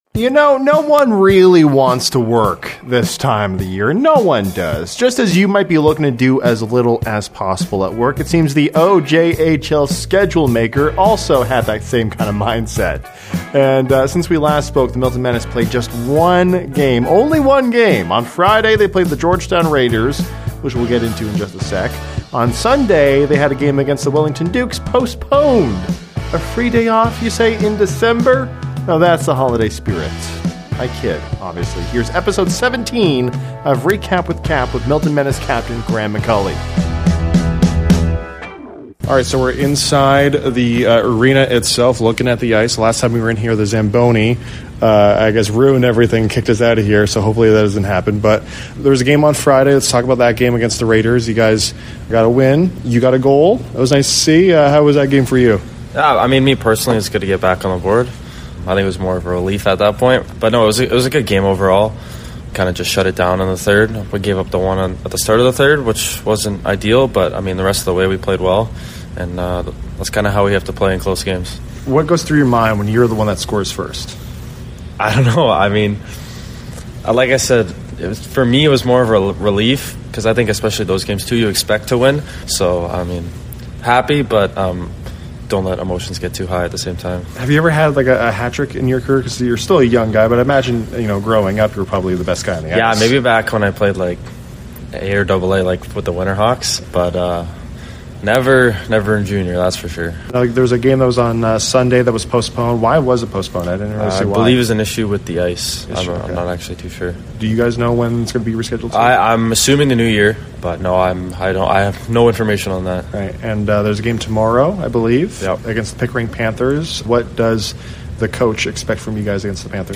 Weekly interview